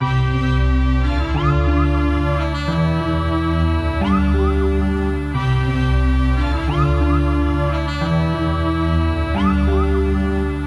描述：理智制造。3个小号叠加一个萨克斯，一个声音，以及有效果的警笛声。
标签： 90 bpm Hip Hop Loops Brass Loops 1.79 MB wav Key : Unknown
声道立体声